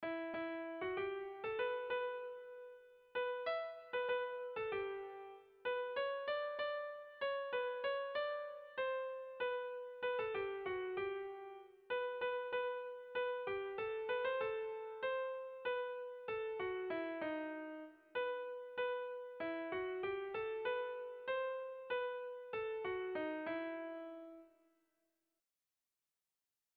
Erromantzea
Zortziko txikia (hg) / Lau puntuko txikia (ip)
AB